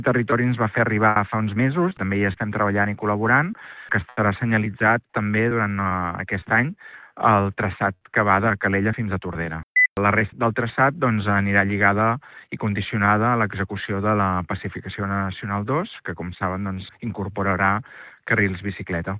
Sobre el traçat local, el conseller comarcal ha avançat que el Departament de Territori ja ha dibuixat la connexió entre Calella i Tordera, que seguirà la línia litoral pels passejos marítims.